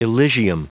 Prononciation du mot elysium en anglais (fichier audio)
Prononciation du mot : elysium